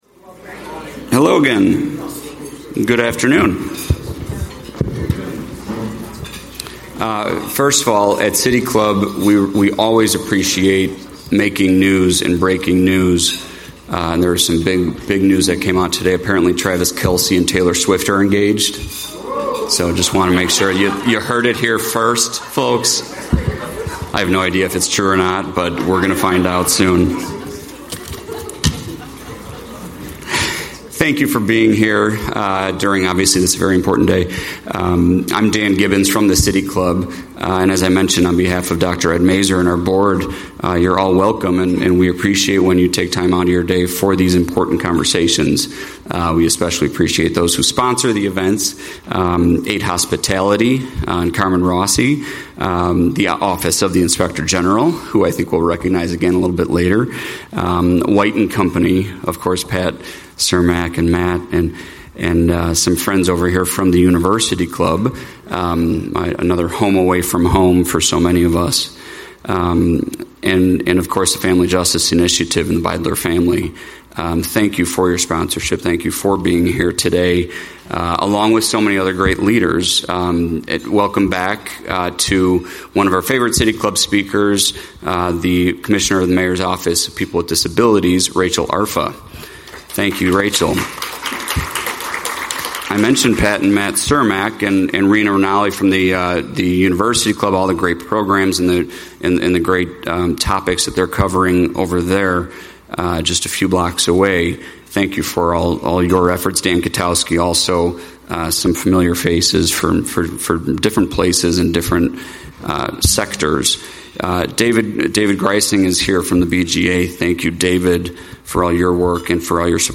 Speaker Inspector General Deborah Witzburg Deborah Witzburg serves as Inspector General for the City of Chicago, having been appointed to her first term in April 2022.